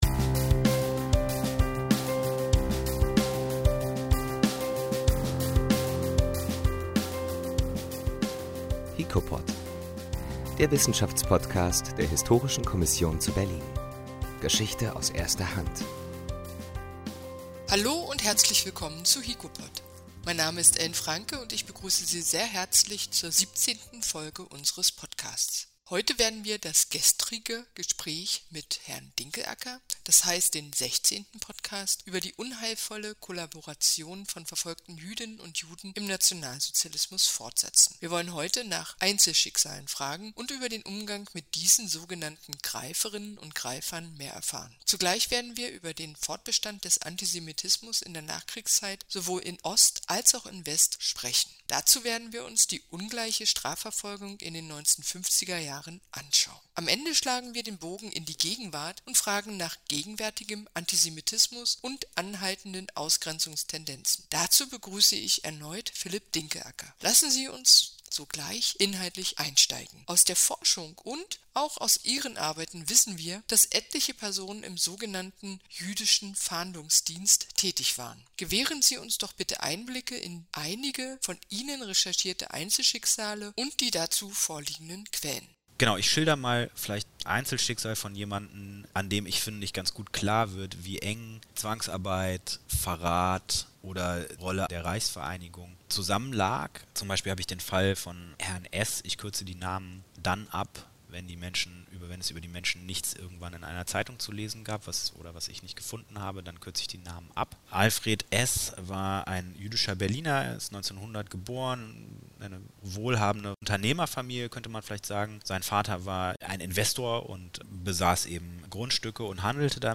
17 ǀ HiKoPod ǀ Antisemitismus in der Berliner Nachkriegsgesellschaft. Fortsetzung des Gesprächs